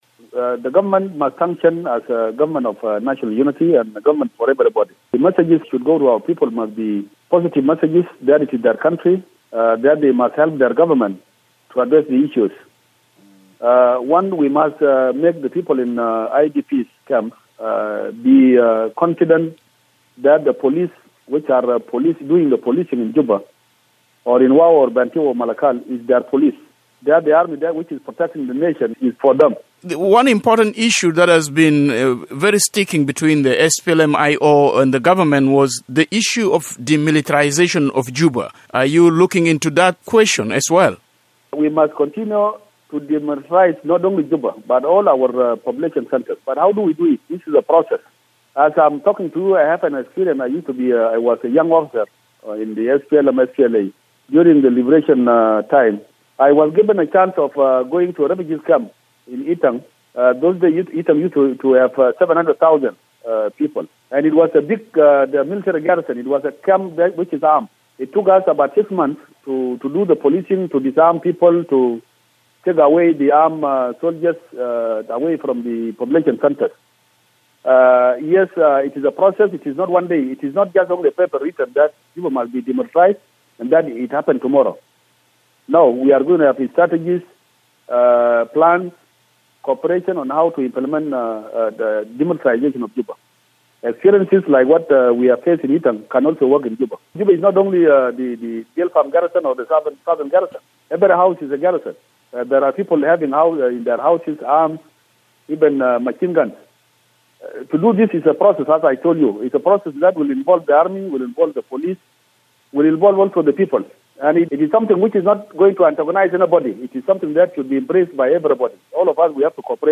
Hours after his appointment as First Vice President, Taban Deng Gai spoke exclusively to South Sudan in Focus about the issues facing the county, including the demilitarization of Juba and restoring trust in the country’s security forces. Deng is also appealing to the country IDP’s to return to their homes and rebuild the country.